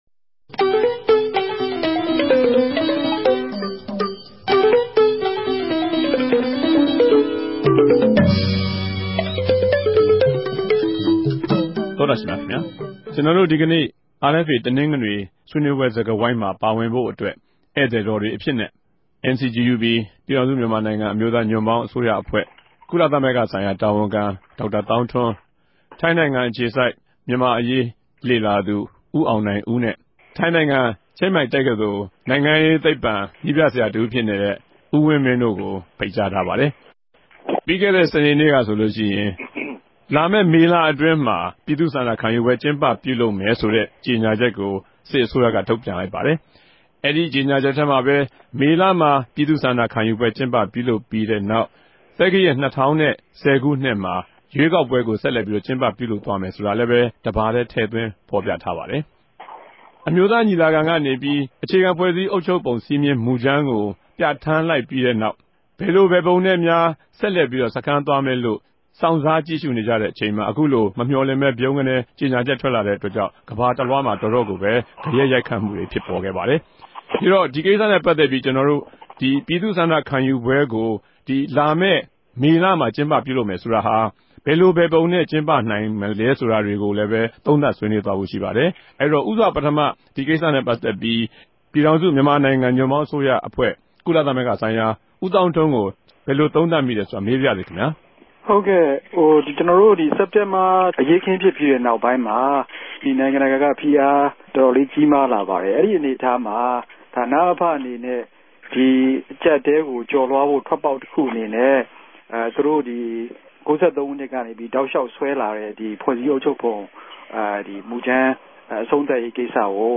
အခုတပတ် တနဂဿေိံြနေႚ အာအက်ဖ်အေ စကားဝိုင်းဆြေးေိံြးပြဲမြာ လာမယ့်မေလ ူမန်မာိံိုင်ငံမြာ ူပည်သူႚဆ္ဋိံခံယူပြဲတရပ်ကို ကဵင်းပူပလြုပ်မယ်လိုႚ စစ်အစိုးရက ေုကညာခဲ့တဲ့ ကိင်္စနဲႚပတ်သက်္ဘပီး ပေၞထြက်လာတဲ့ ဂယက်ရိုက်ခတ်မြတြေ၊ ဆ္ဋိံခံယူပြဲကို ဘယ်လိုပုံစံမဵိြးနဲႚ ကဵင်းပိံိုင်မလဲ၊ ူပည်သူလူထုက အူပည့်အဝ မထောက်ခံရင် ဘယ်လိုူဖစ်လာိံိုင်မလဲ ဆိုတာတေကြို သုံးသပ်ဆြေးေိံြးထားပၝတယ်။